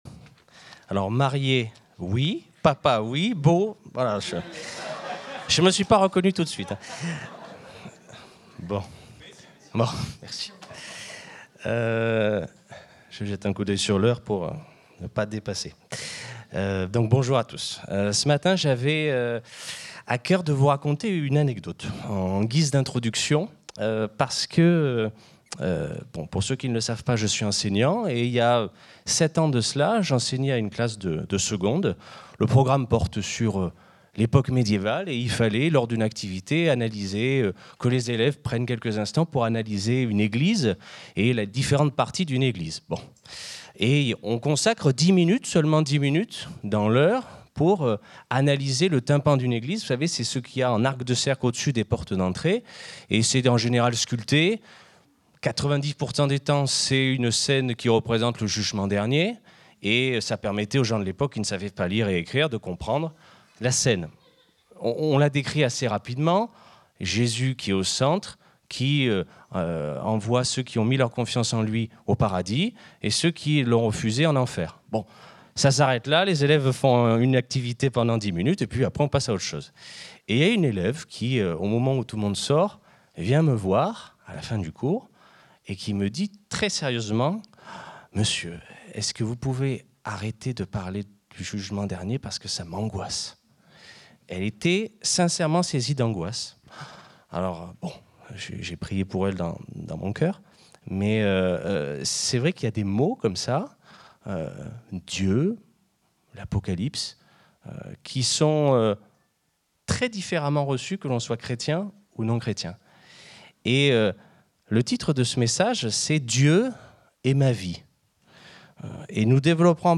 Culte du 18 mai 2025, prédication